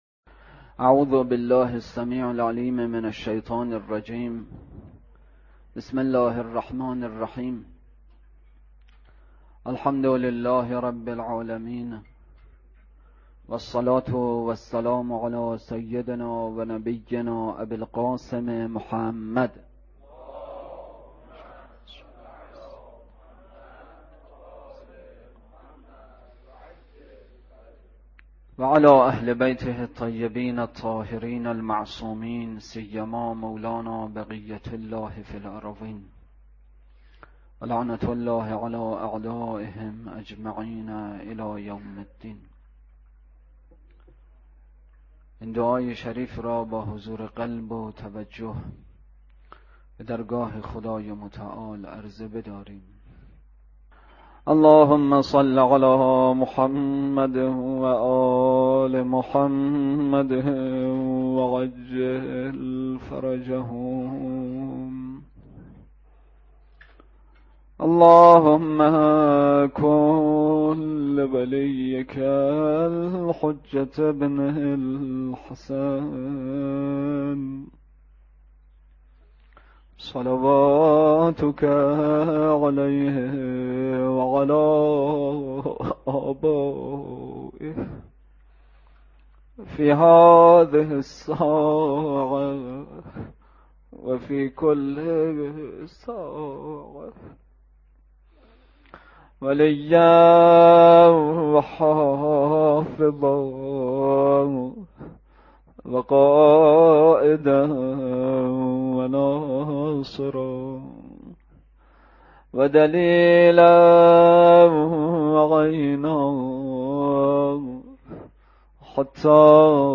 روضه: روضه‌ی حضرت عباس(ع)